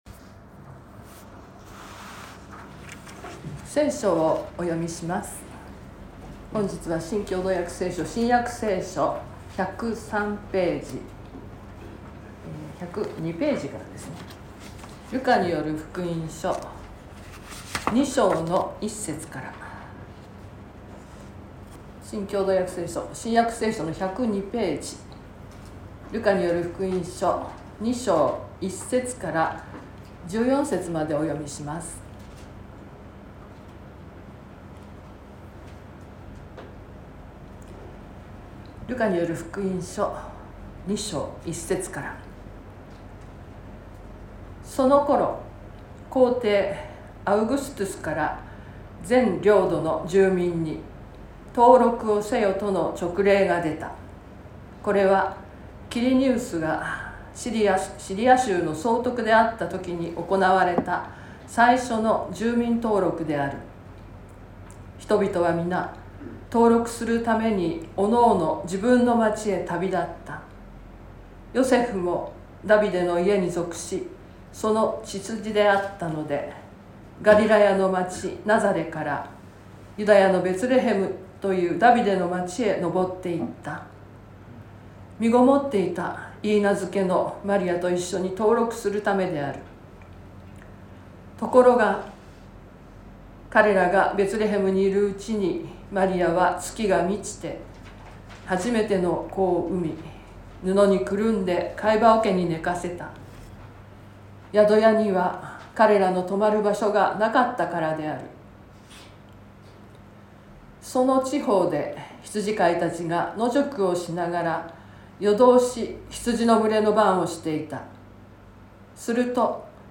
説教アーカイブ。
Youtubeで直接視聴する 音声ファイル 礼拝説教を録音した音声ファイルを公開しています。